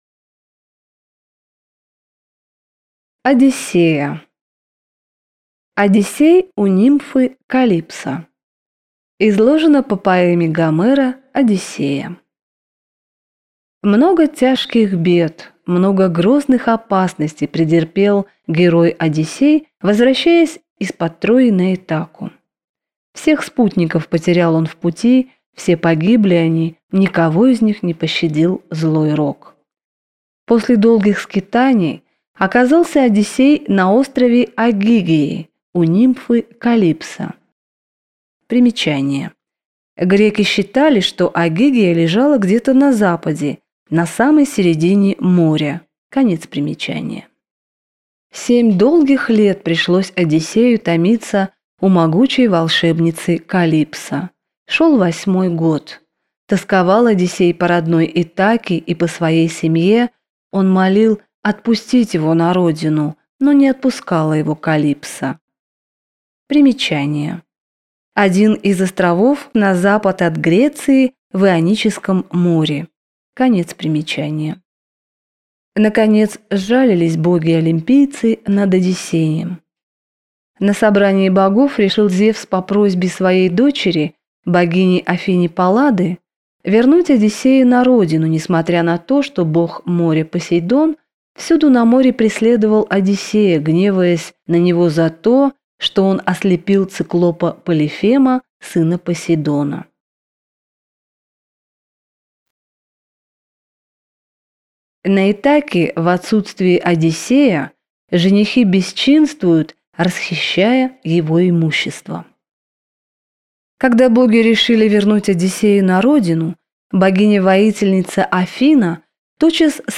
Аудиокнига Легенды и мифы древней Греции. Одиссея | Библиотека аудиокниг